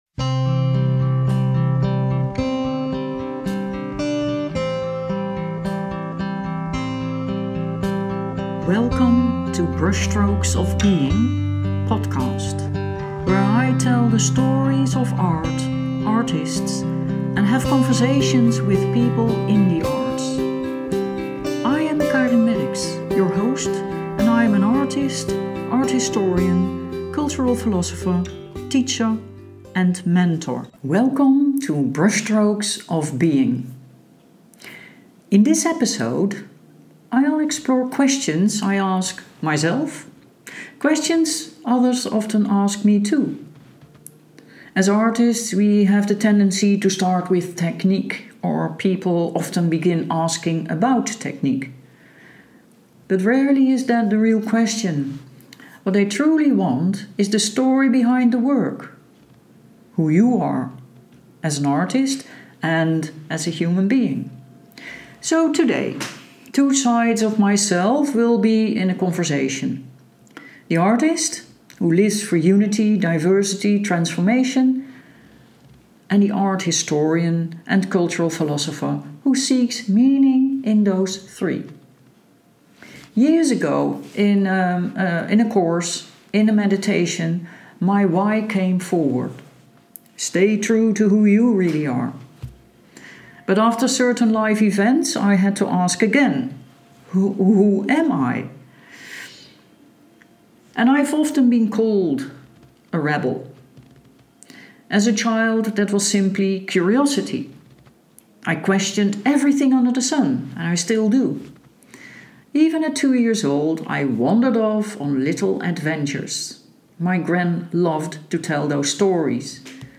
In this episode of Brushstrokes of Being, The Double Chair, I bring two voices into dialogue: the Artist, the Philosopher, and the art historian.